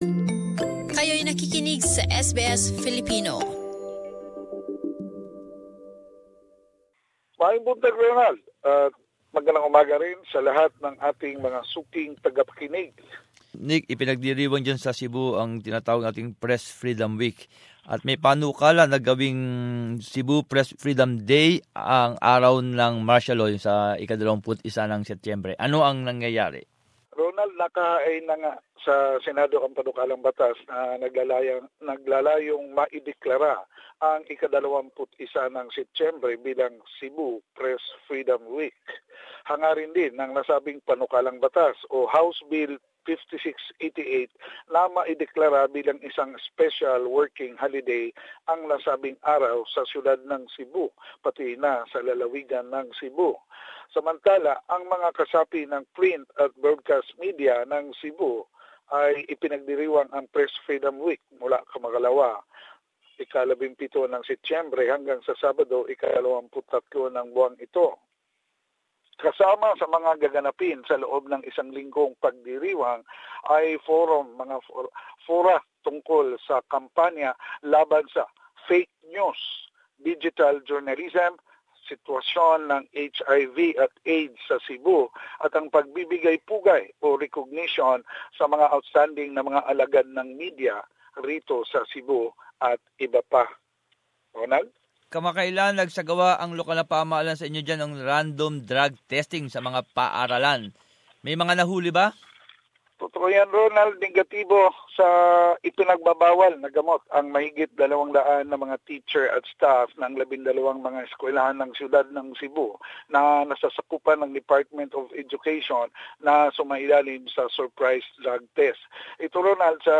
Balitang Bisayas. Summary of latest news in the region